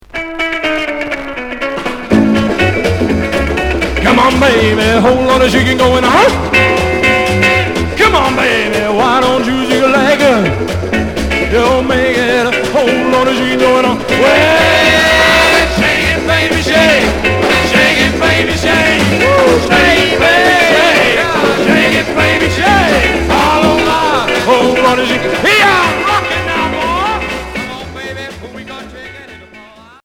Rock'n'roll